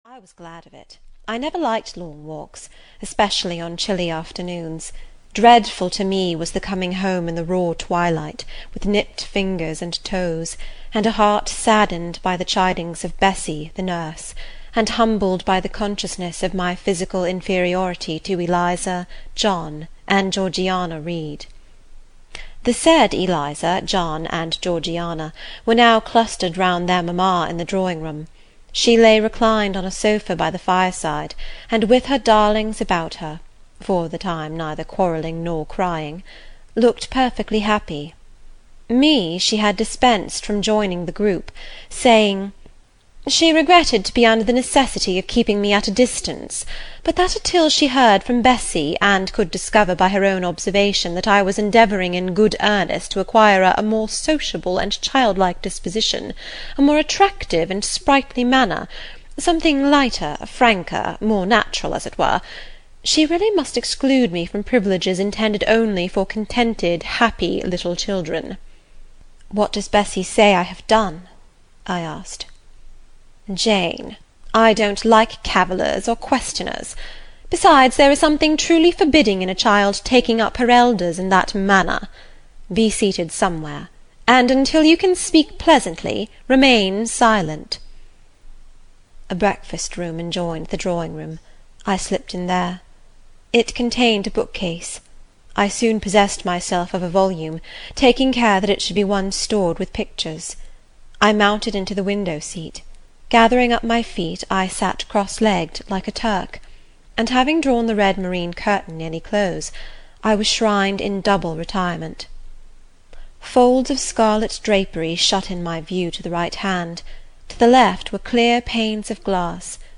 Jane Eyre (EN) audiokniha
Ukázka z knihy